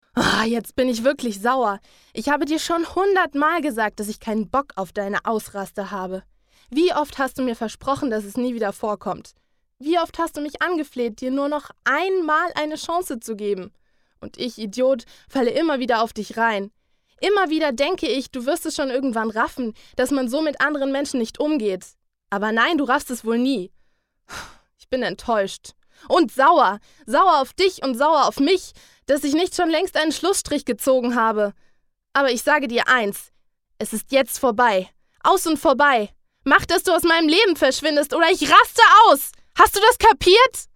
Sprecherin deutsch
Kein Dialekt
Sprechprobe: eLearning (Muttersprache):
german female voice over artist